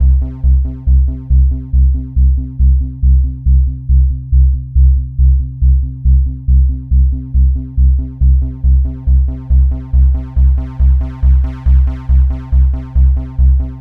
TSNRG2 Bassline 001.wav